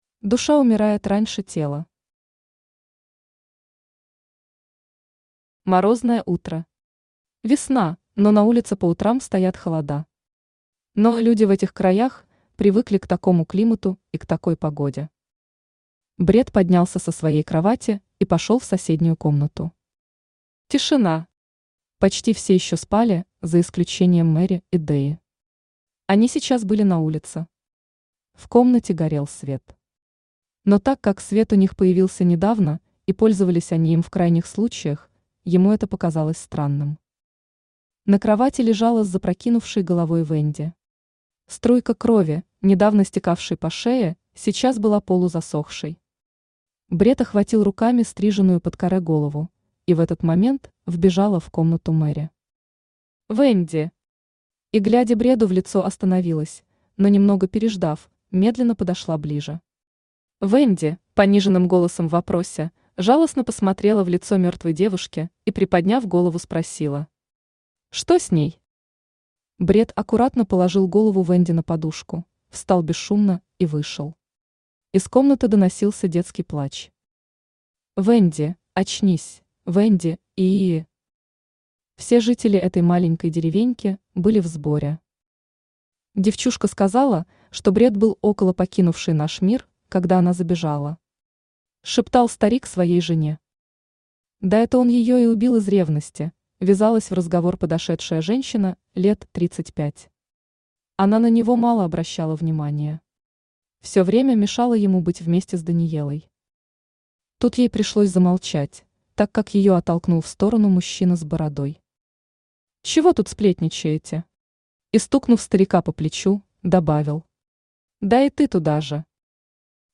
Аудиокнига Осуждённый | Библиотека аудиокниг
Aудиокнига Осуждённый Автор Алёна RMK Читает аудиокнигу Авточтец ЛитРес.